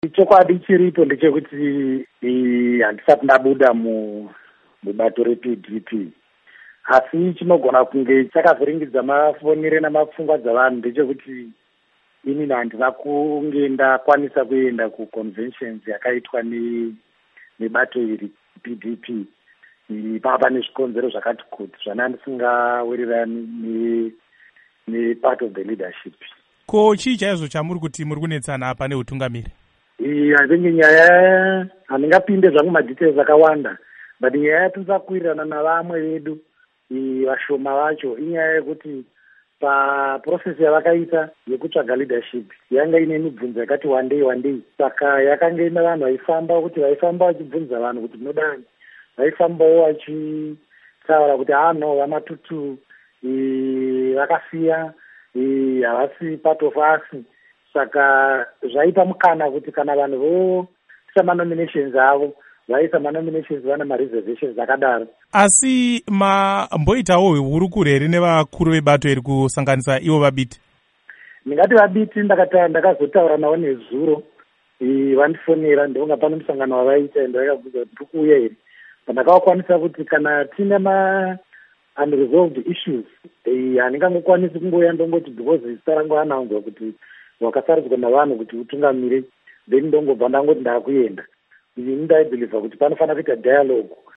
Hurukuro naVaTongai Matutu